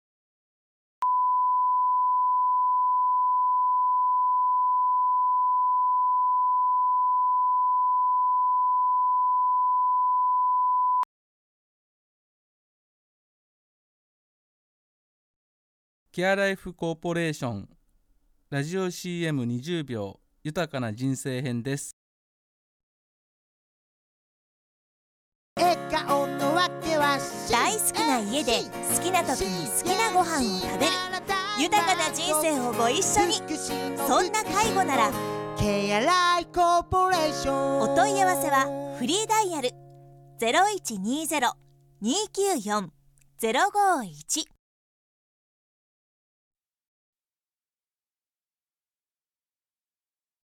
「福祉の事ならCLC！」を耳に残るサウンドロゴとCLCマン（キャラクター）を使って展開
ラジオCM